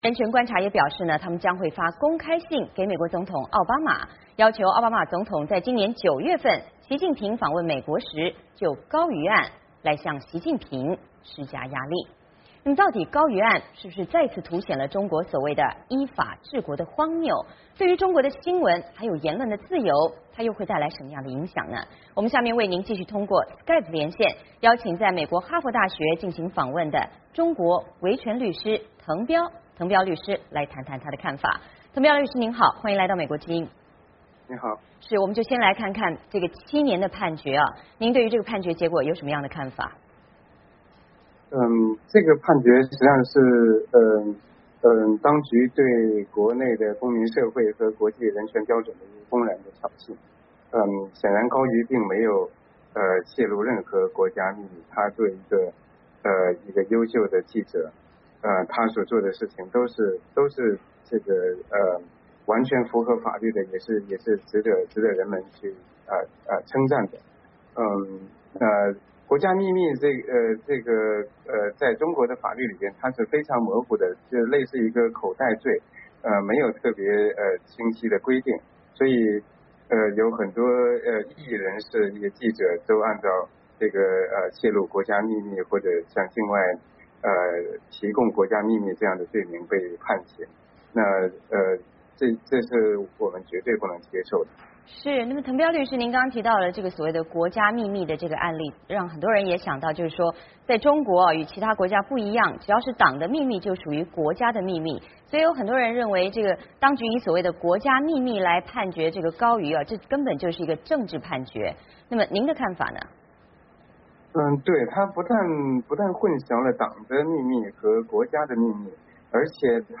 高瑜案是否再次凸显中国所谓依法治国的荒谬，对中国新闻自由又将带来什么样的影响与伤害？我们请在美国哈佛大学访问的中国维权律师腾彪谈谈他的看法。